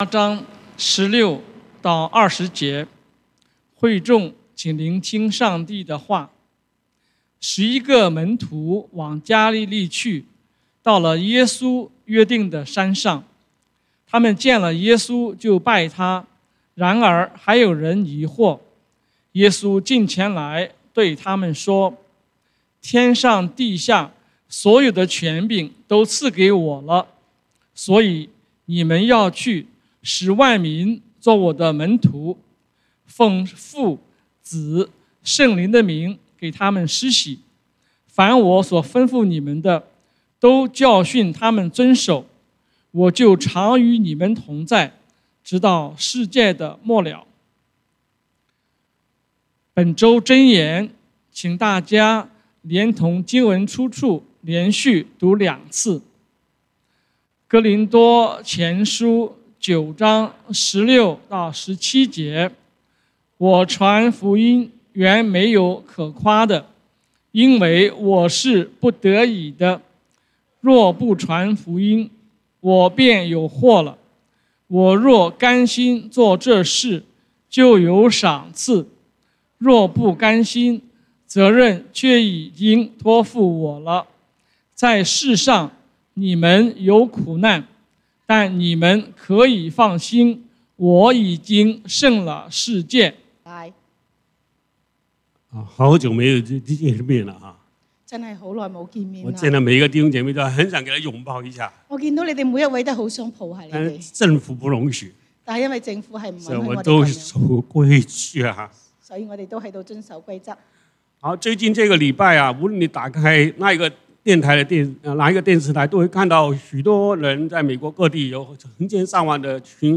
講道經文：《馬太福音》Matthew 28:16-20 本週箴言：《哥林多前書》1 Corinthians 9:16-17 「我傳福音原沒有可誇的，因為我是不得已的。